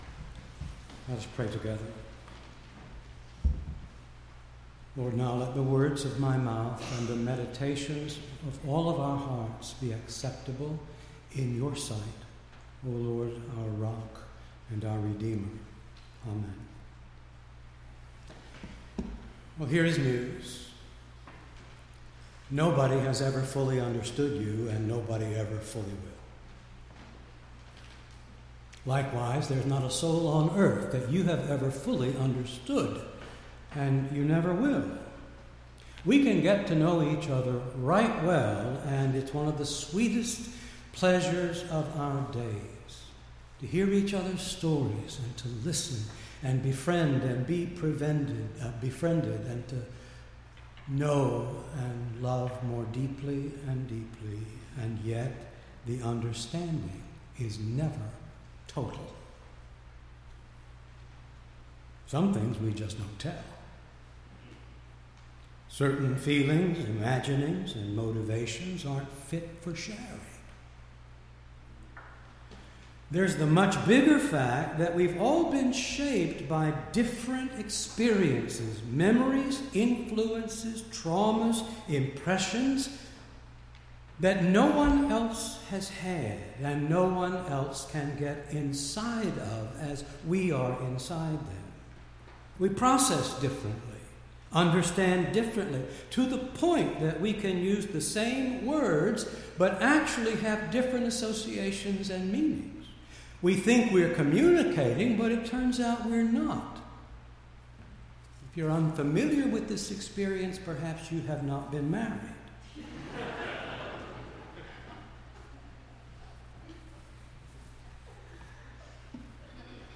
8-14-16-sermon.mp3